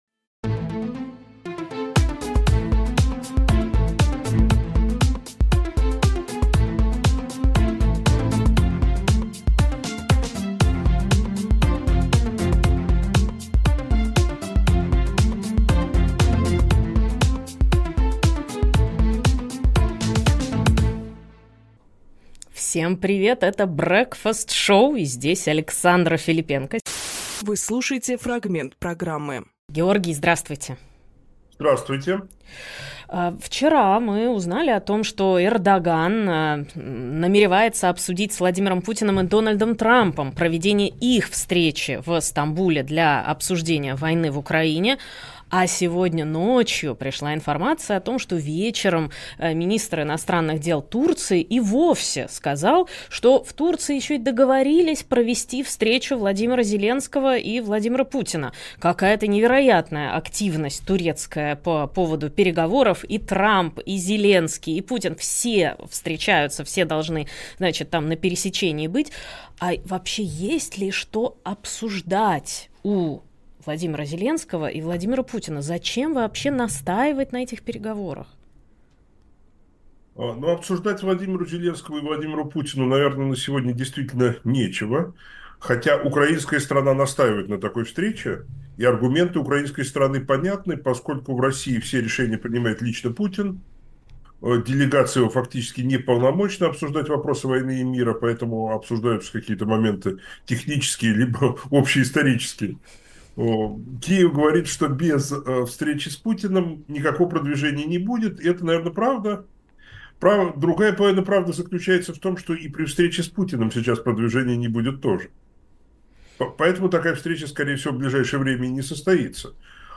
Фрагмент эфира 26.07.25